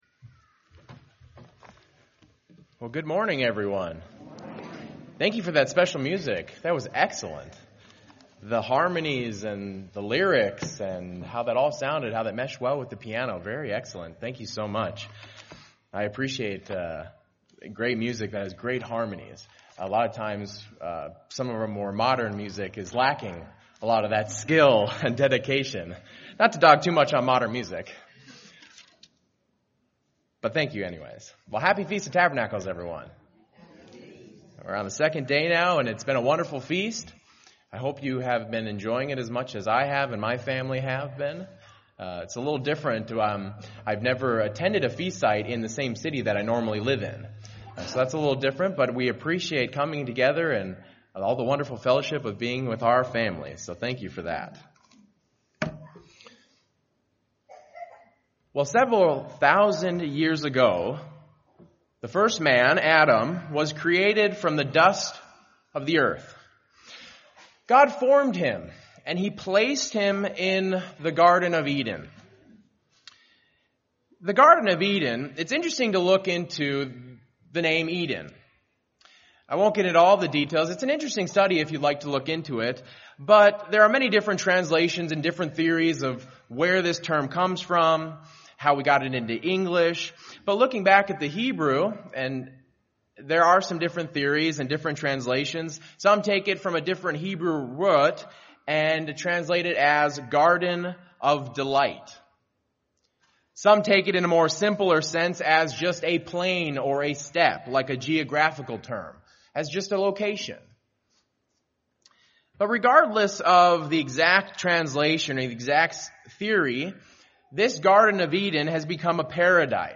This sermon was given at the Cincinnati, Ohio 2019 Feast site.